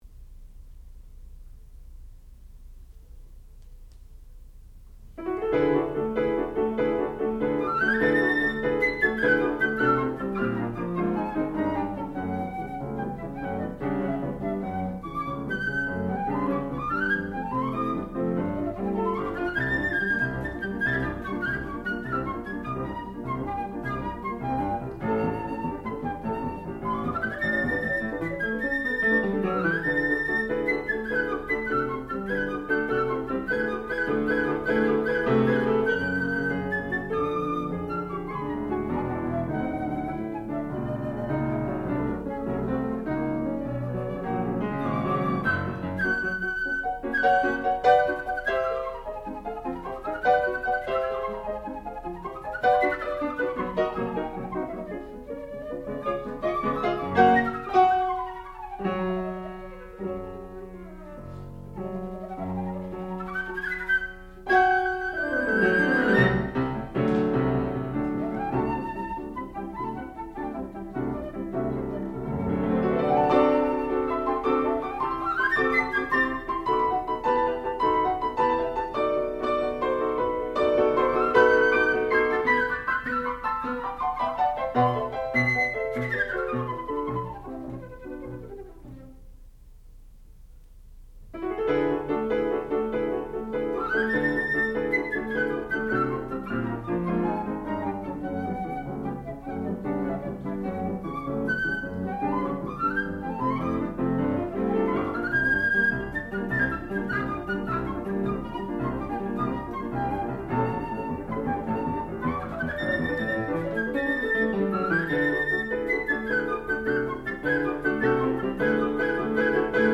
sound recording-musical
classical music
flute
piano
Master's Recital